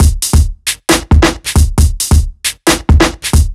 Top Df Break 135.wav